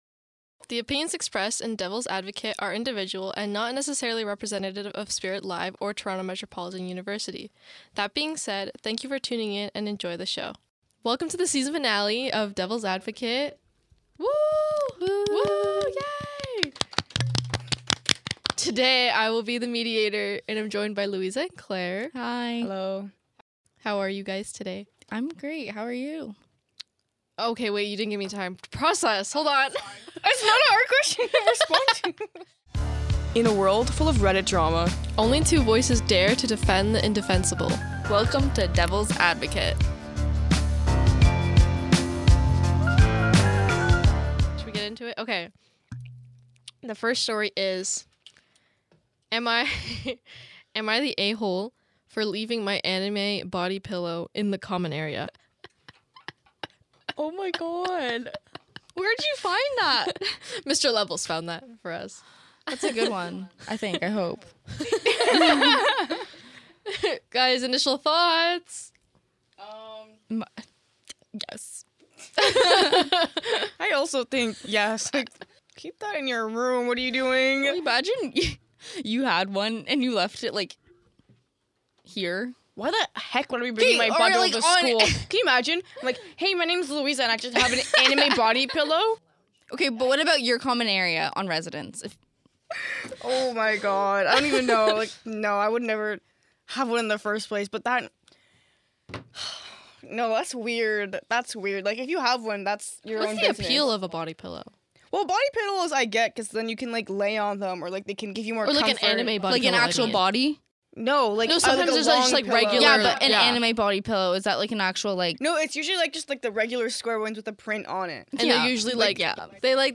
Each week three hosts will debate and discuss a Reddit post from the thread “Am I an A-hole?”. These debates will be between two hosts, one against and one for, while the third host reads, moderates and acts as a commentator of the discussion.